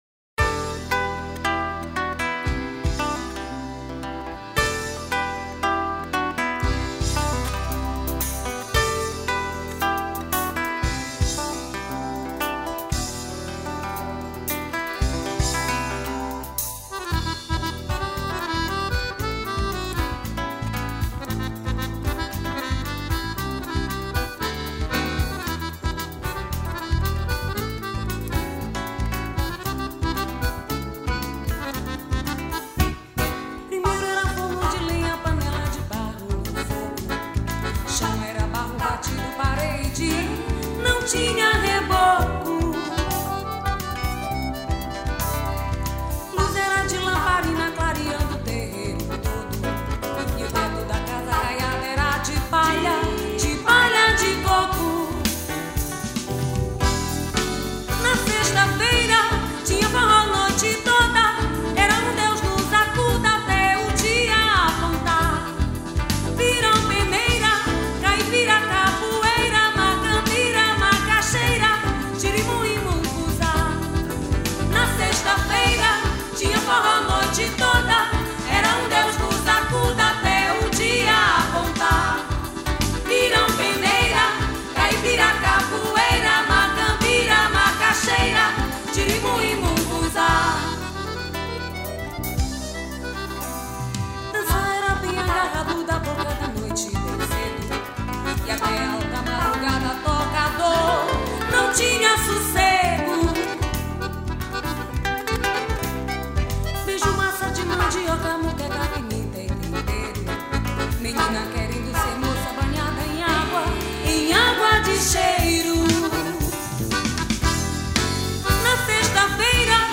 48   03:54:00   Faixa:     Xote
Violao Acústico 6
Baixo Elétrico 6